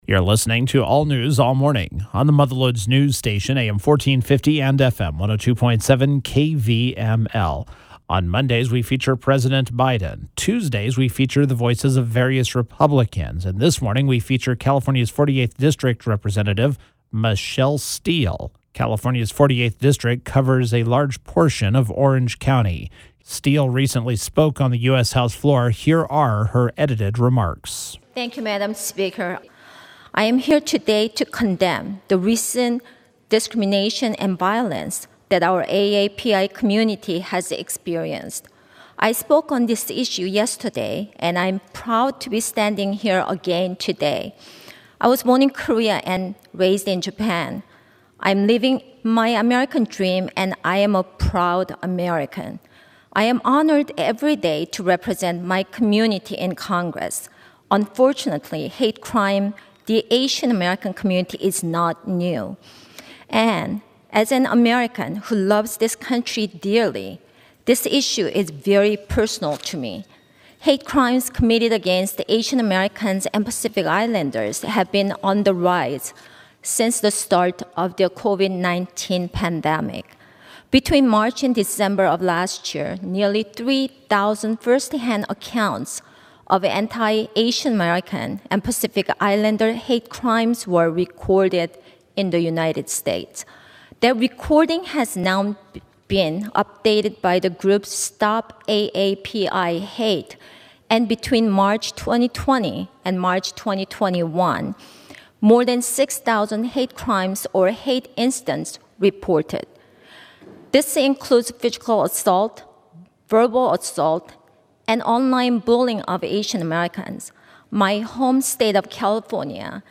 Republican Rep. Michelle Steel (CA-48) spoke on the House Floor about the rise in Asian American and Pacific Islander (AAPI) hate, and her bipartisan resolution introduced earlier this year to condemn the hate crimes and hate incidents many in the AAPI community have faced.